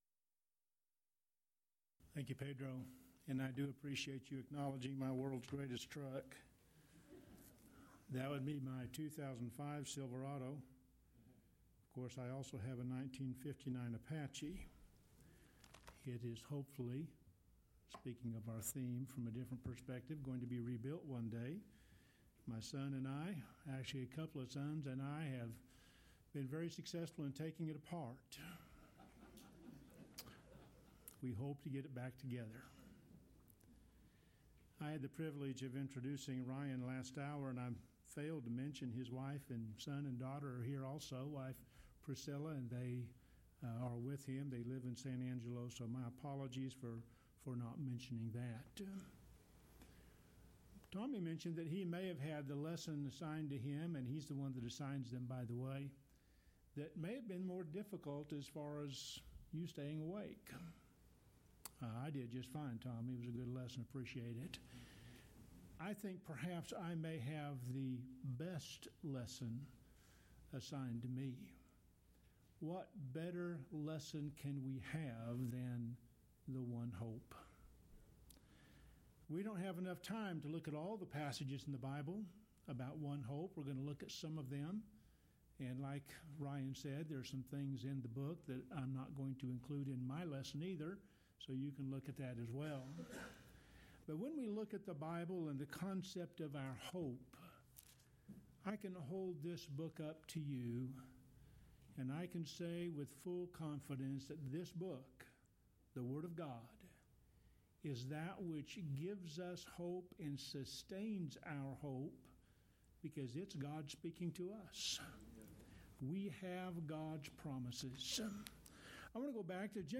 Alternate File Link File Details: Series: Lubbock Lectures Event: 23rd Annual Lubbock Lectures Theme/Title: A New Heaven and a New Earth: Will Heaven Be On A "New Renovated" Earth?
If you would like to order audio or video copies of this lecture, please contact our office and reference asset: 2021Lubbock04 Report Problems